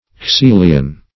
Caecilian \C[ae]*cil"i*an\ (?; 106), n. [L. caecus blind. So